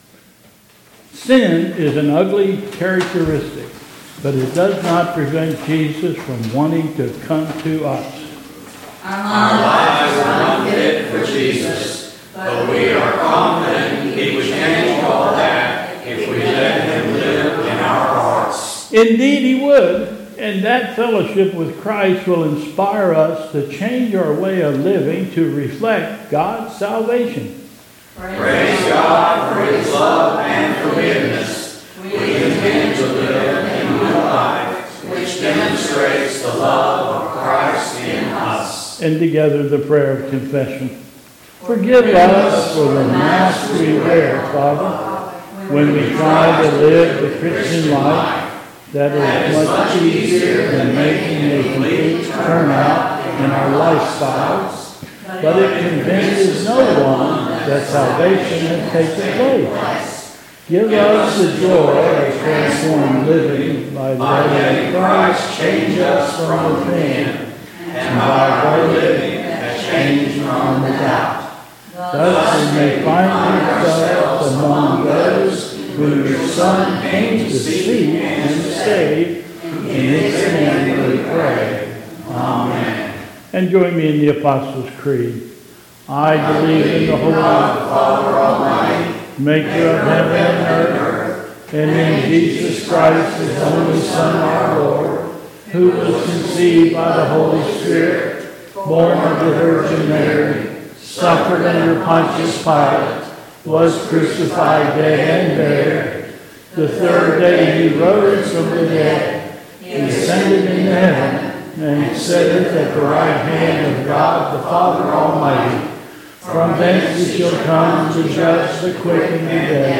Service of Favorite Hymns